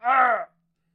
argh3.ogg